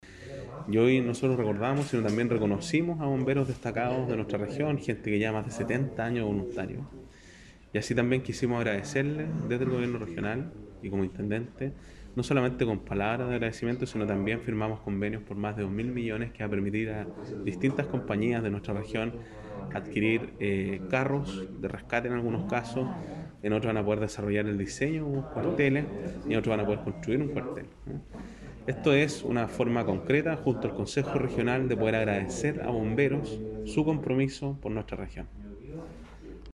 El Intendente Sergio Giacaman lideró la celebración de un nuevo “Día del Bombero”. La ceremonia se llevó a cabo con un número reducido de asistentes y tuvo como objetivo agradecer la labor de la institución en la Región del Biobío.
01-intendente-bomberos.mp3